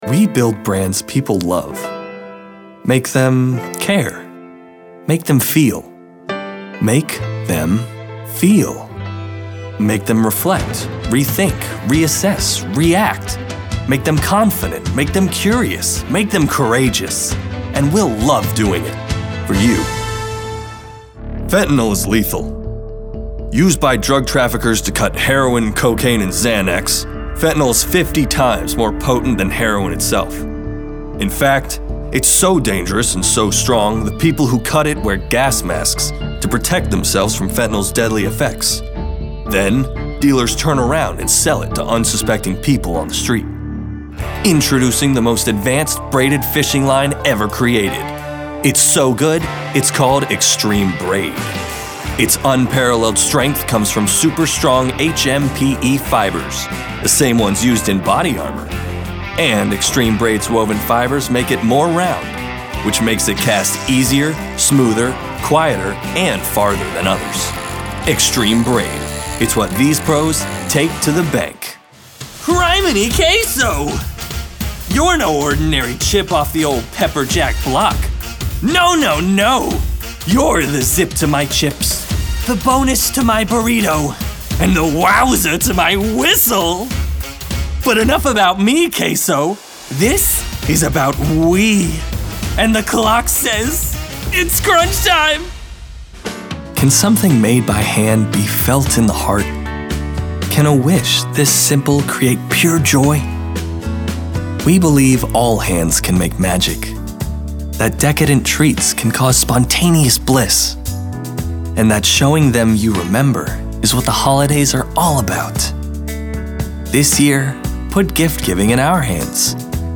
A bright male voice, with amazing range!
announcer, anti-announcer, caring, confident, conversational, friendly, genuine, high-energy, informative, mellow, motivational, perky, smooth, thoughtful, upbeat, warm